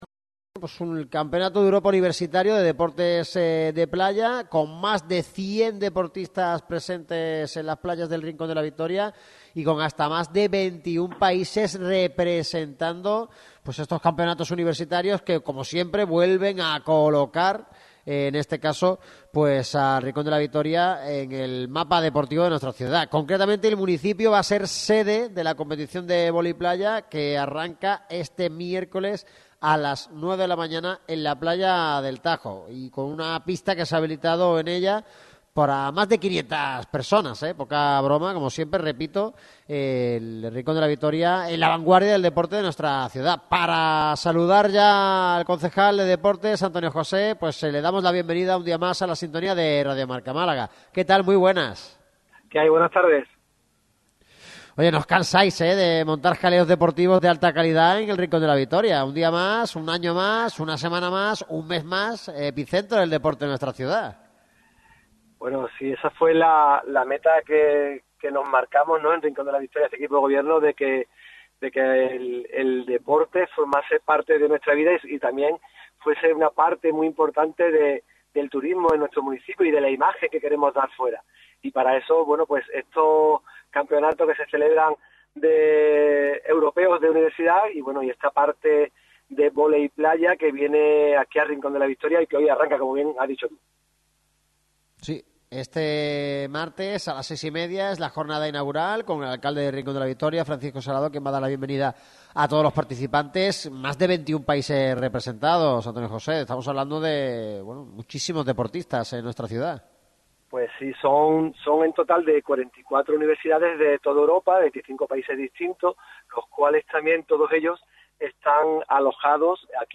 Antonio José Martín, concejal de deportes, atendió a Radio MARCA Málaga e incidió en la repercusión que esta prueba deportiva tendrá en el municipio.